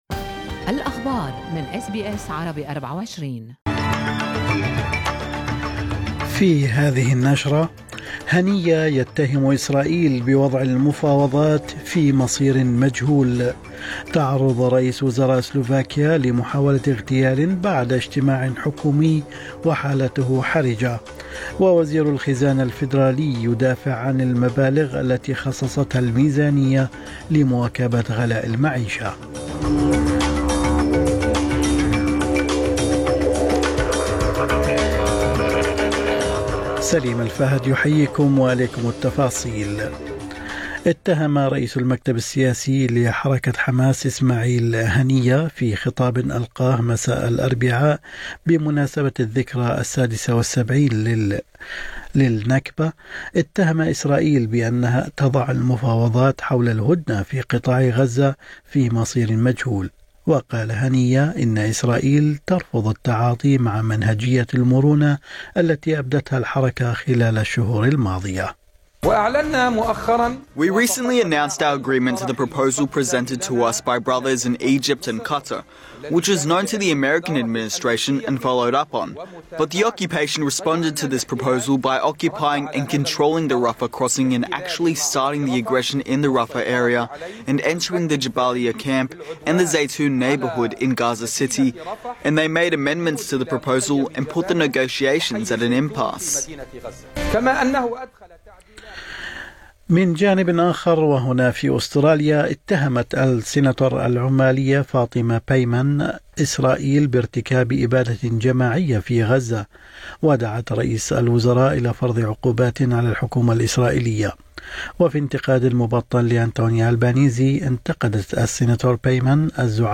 نشرة أخبار الصباح 16/5/2024